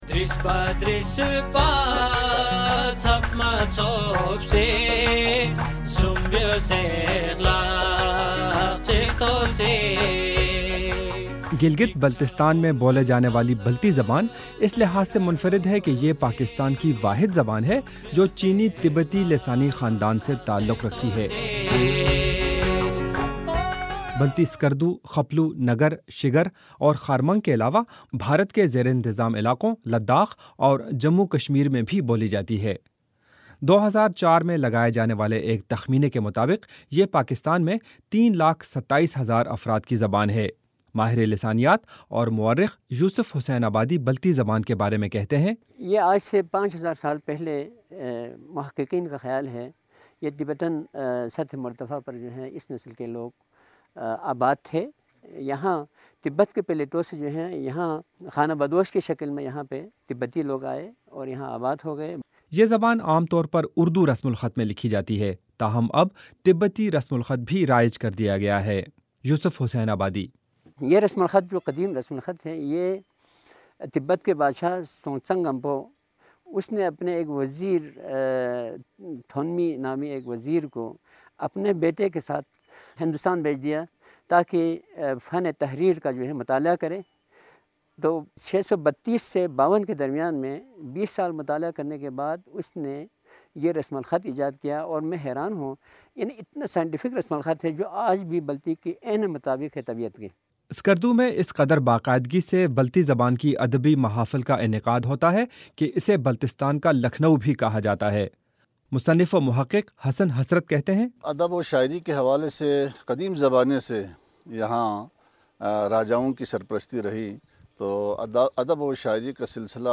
رپورٹ